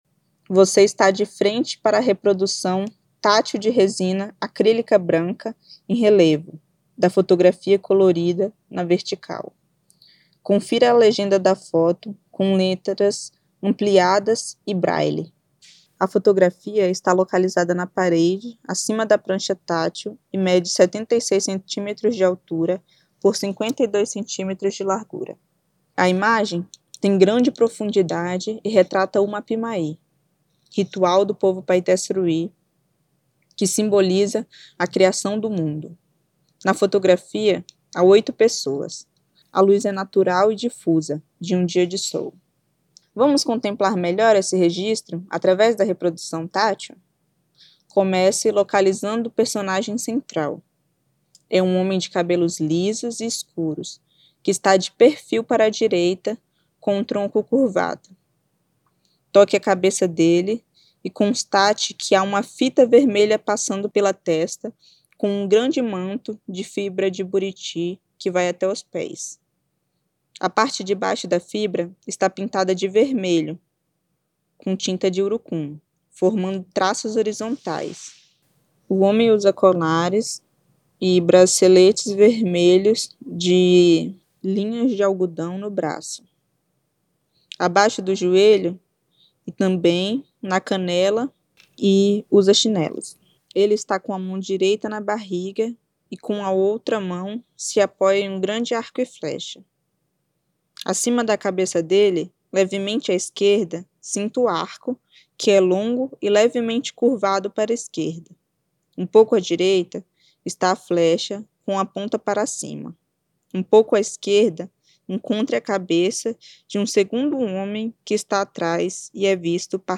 Audiodescrição